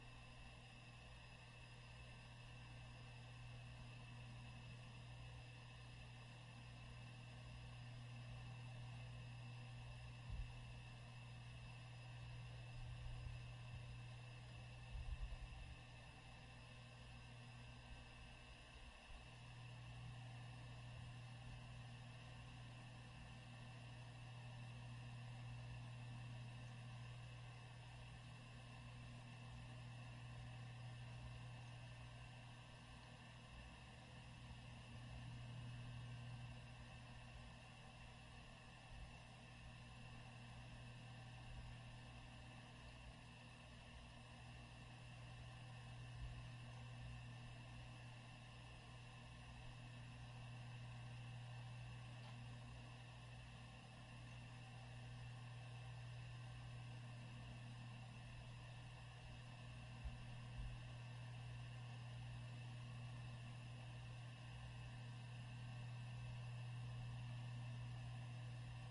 随机 " 计算机光盘驱动器打开关闭+运行
描述：电脑CD驱动器打开关闭+ run.flac
标签： 打开 关闭 计算机 光盘 驱动器 运行
声道立体声